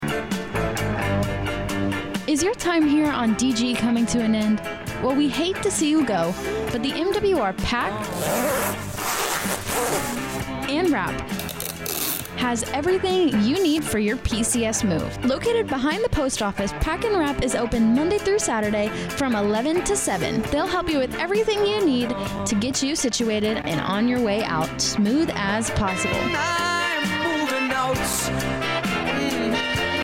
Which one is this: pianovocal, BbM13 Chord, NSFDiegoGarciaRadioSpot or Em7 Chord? NSFDiegoGarciaRadioSpot